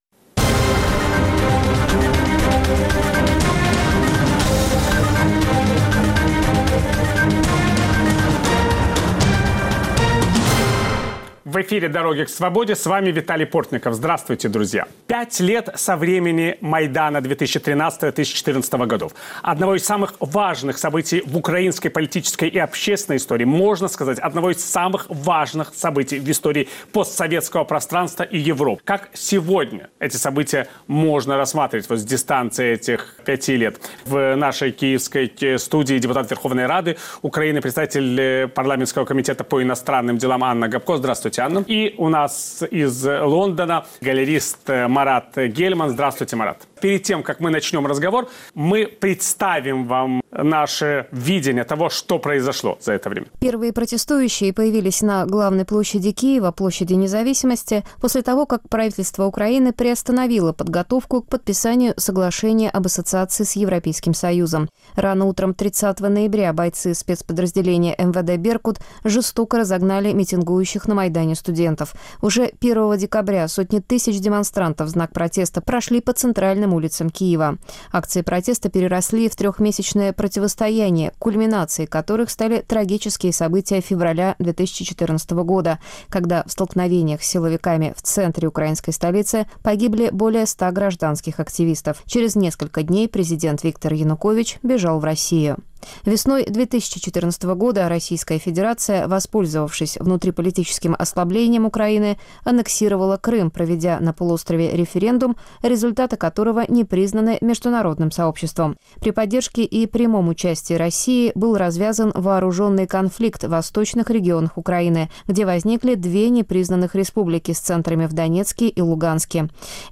Как изменилась Украина за 5 лет после Майдана? Как повлияли украинские революции на постсоветское пространство? Виталий Портников беседует с депутатом Верховной Рады Украины Анной Гопко и публицистом Маратом Гельманом